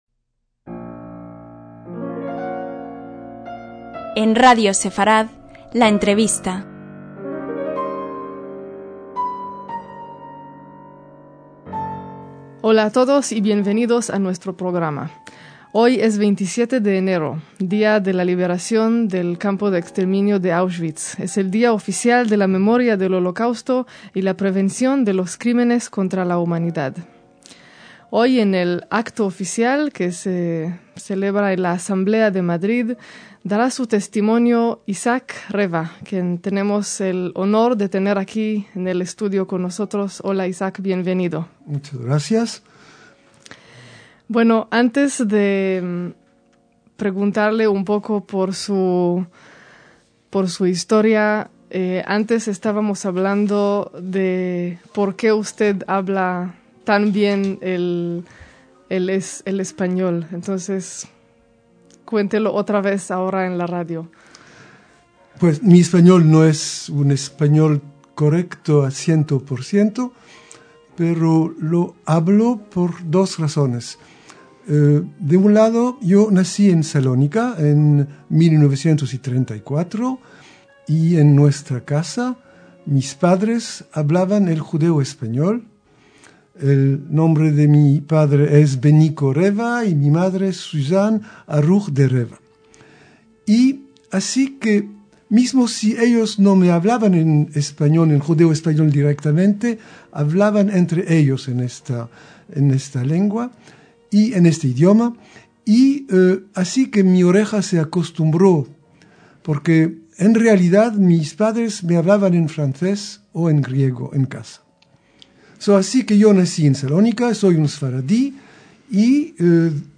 En recuerdo de su historia vital, reponemos la entrevista que nos concedió hace una década.